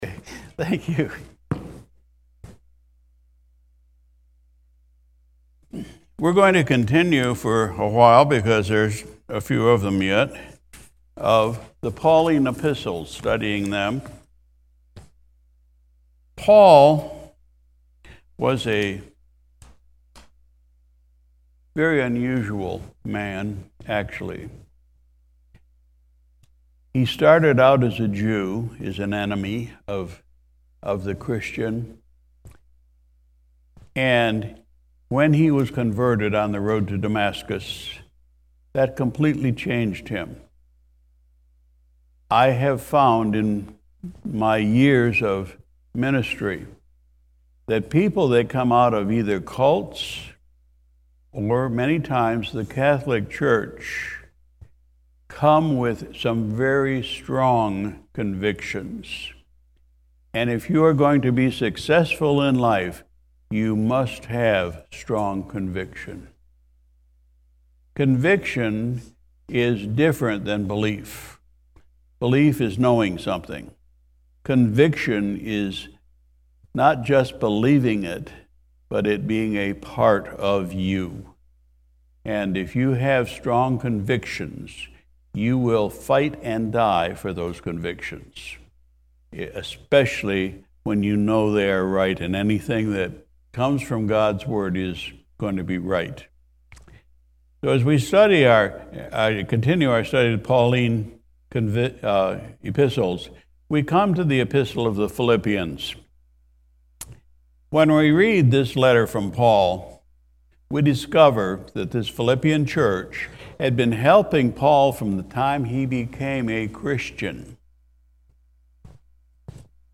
April 30, 2023 Sunday Evening Service We studied in the Book of Philippians